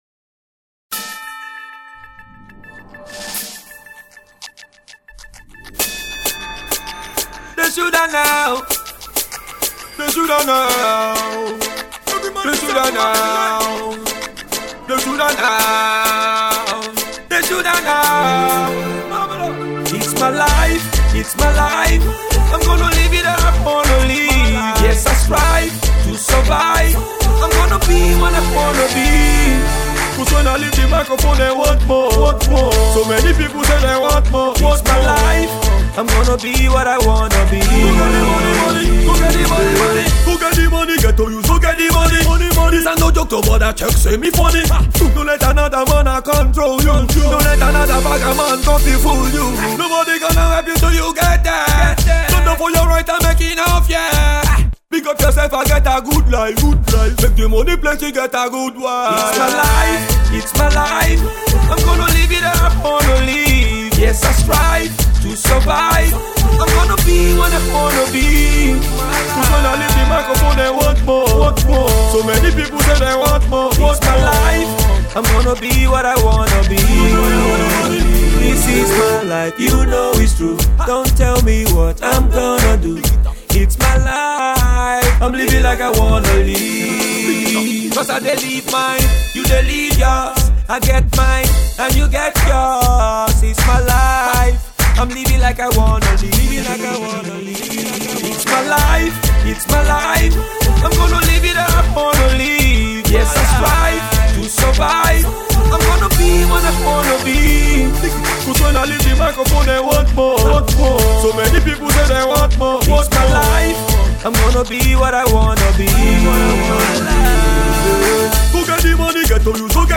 is slower and much more grittier